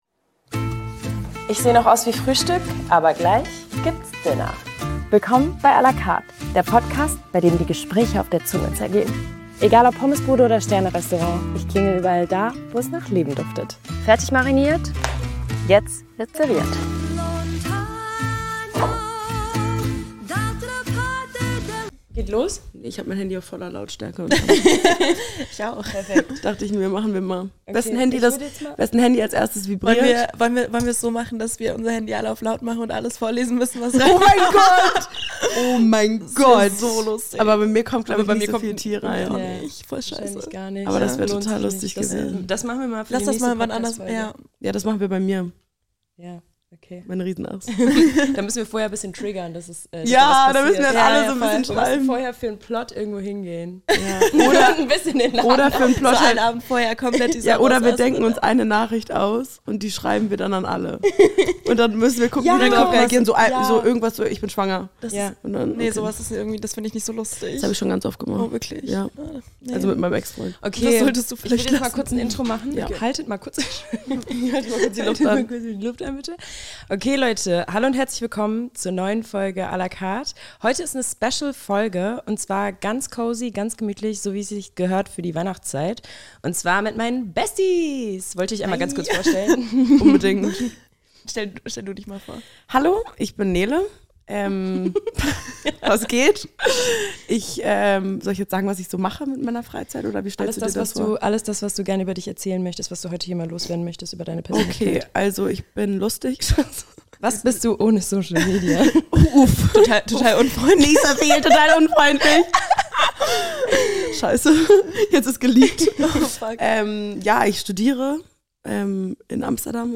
treffen sich heute zu Hause zu der ersten Special-Folge! Die drei sprechen in der vierten Folge „à la Carte“ über die Freundschaft unter Frauen und ob Boyfriends wirklich peinlich sind.